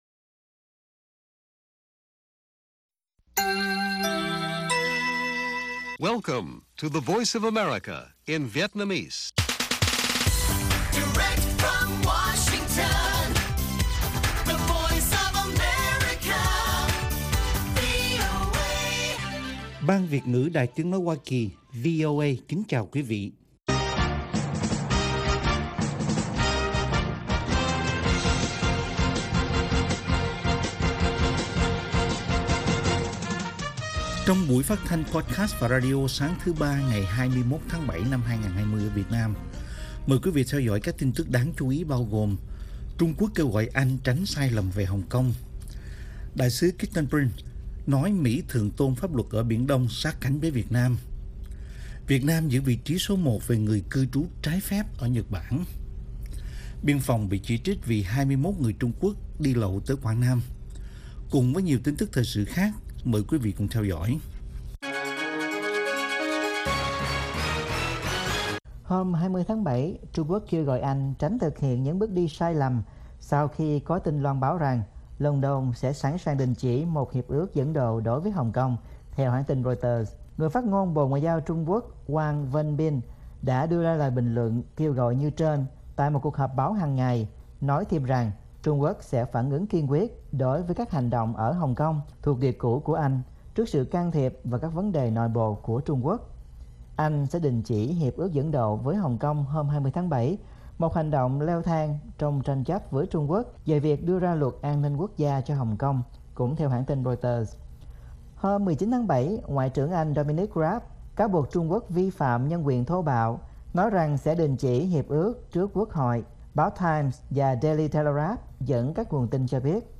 Bản tin VOA ngày 21/7/2020